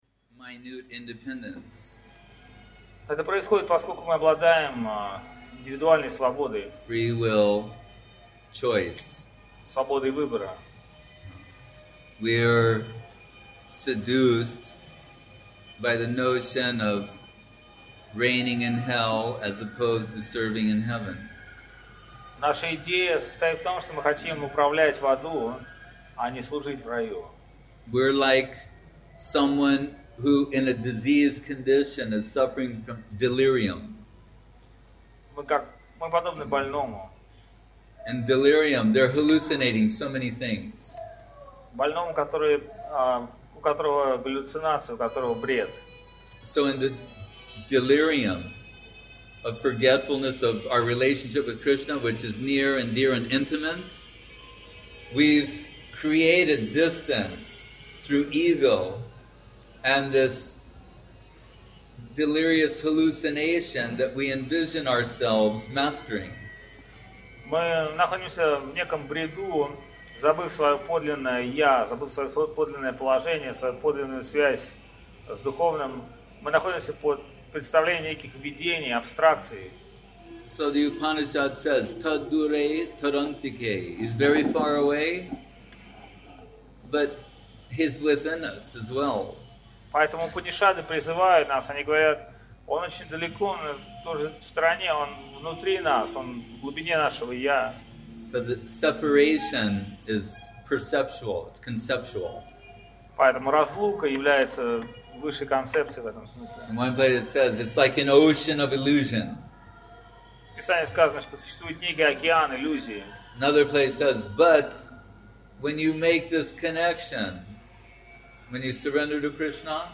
Место: ШЧСМатх Навадвип